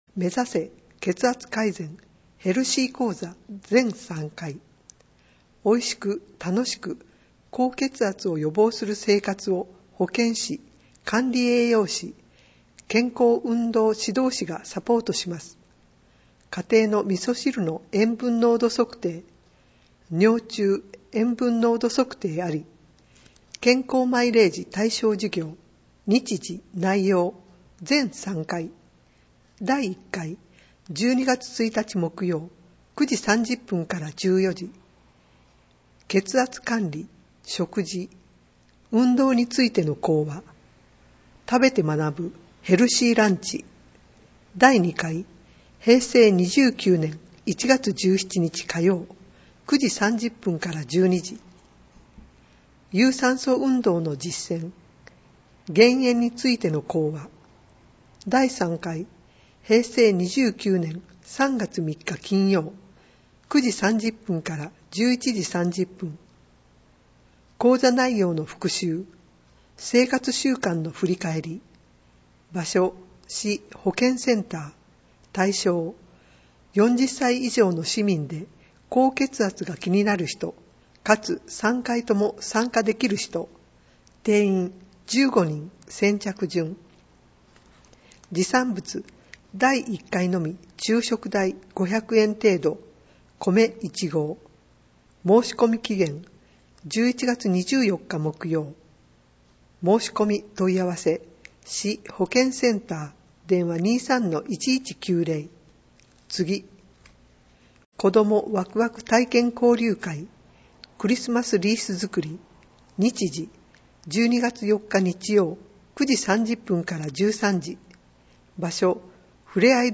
広報やない平成28年10月27日号 [PDFファイル／2.55MB] 市政だより（P1～4） [PDFファイル／1.36MB] テキスト版 音声ファイル [6.86MB] 市政だより（P5～6）／市長コラム（P7）／11月の子育て支援（P8） [PDFファイル／1.7MB] テキスト版 音声ファイル [7.7MB] 音訳版デイジー図書広報はこちらからダウンロードできます [14.22MB] 「音訳しらかべの会」の皆さんによる声の広報（音訳版広報）を、発行後1週間程度で掲載しています。